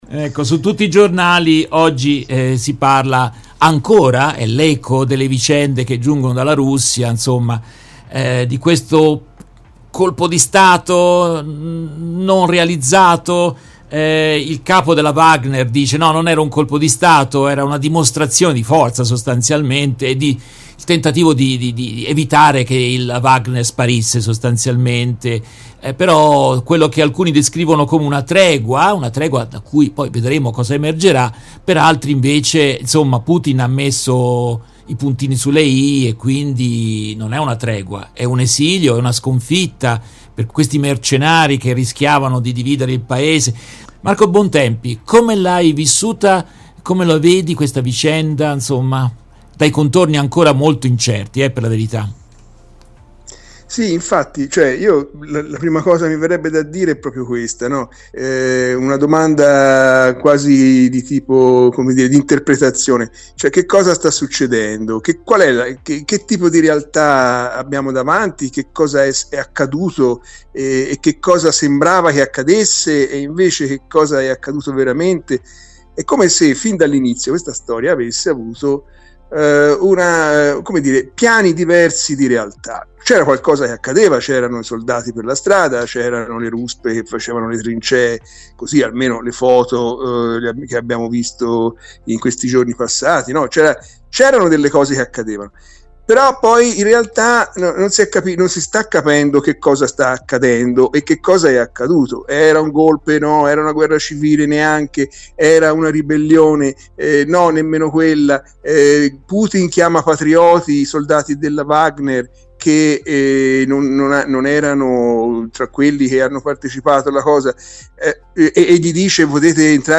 In questa intervista tratta dalla diretta RVS del 27 aprile 2023